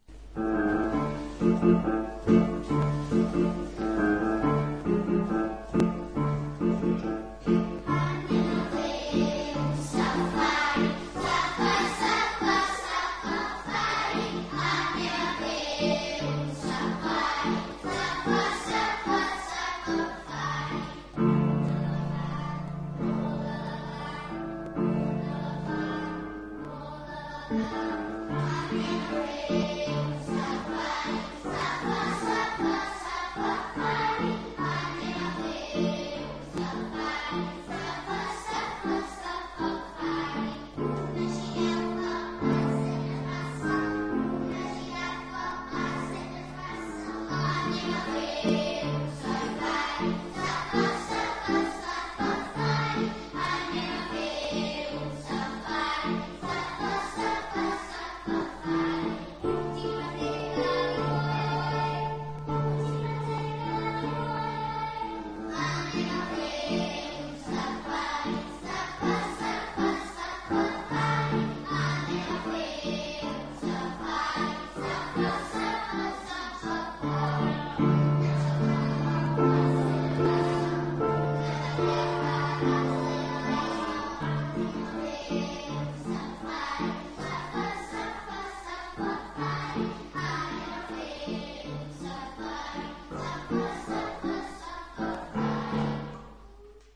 Escolar
Cançó "Anem a fer un safari"
Musical
Cançó interpretada per l'alumnat de l'Escola Les Basseroles de Sant Miquel de Balenyà, nucli de població que pertany a la localitat de Seva.